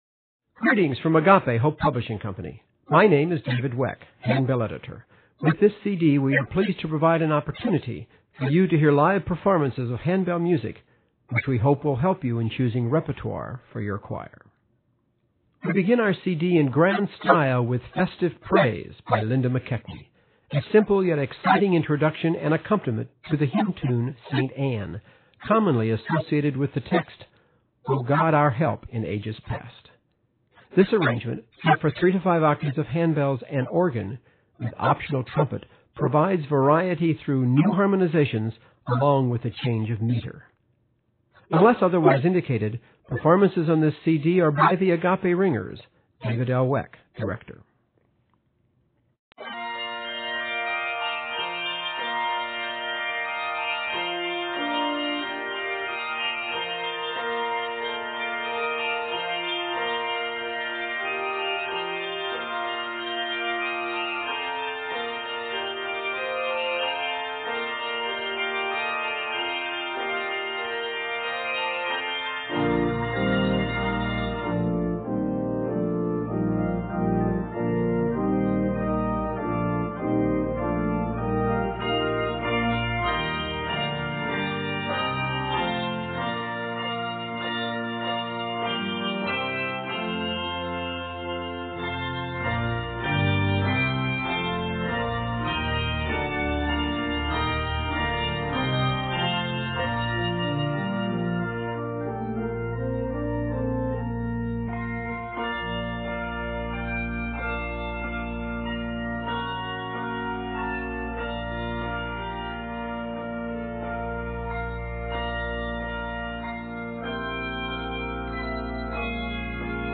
The organ part is not optional.